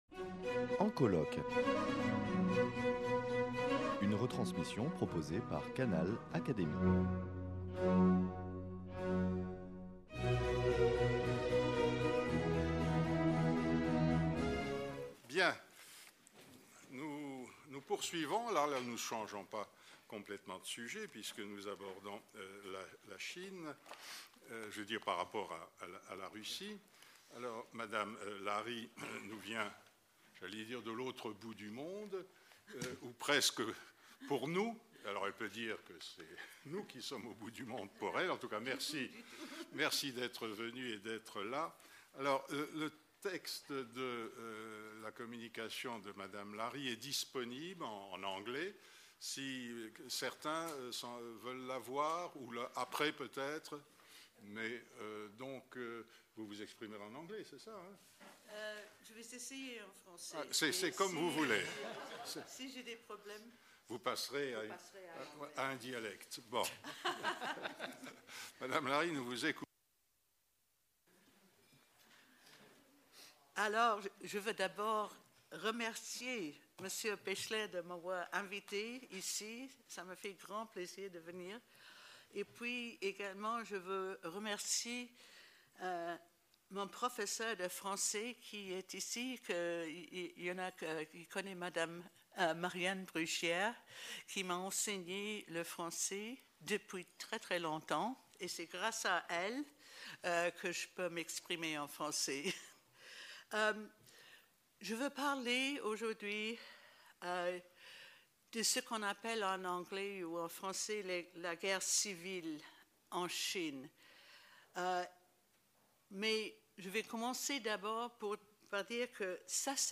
Retransmission du colloque international « La guerre civile » - partie 8